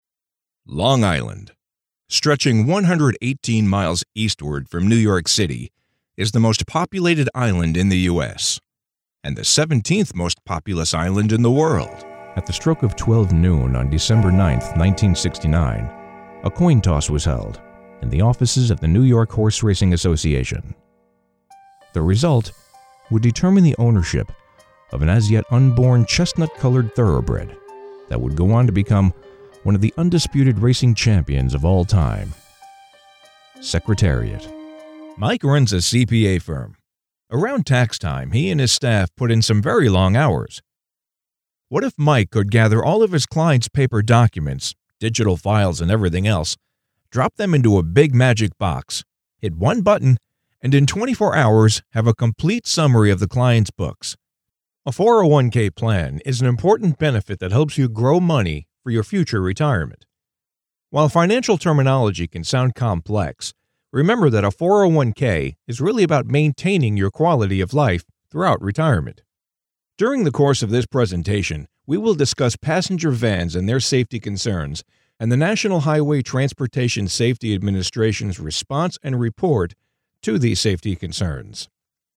mid-atlantic
Sprechprobe: Sonstiges (Muttersprache):